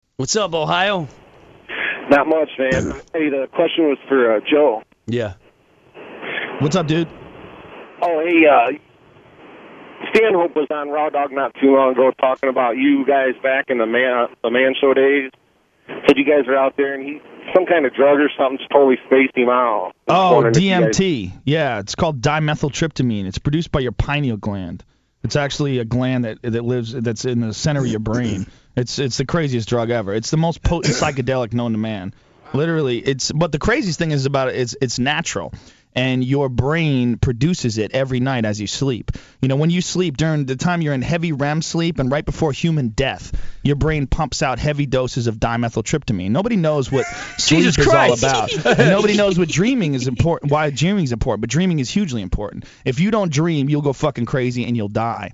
Fear Factor's Joe Rogan talks about DMT
Joe_Rogan-DMT.mp3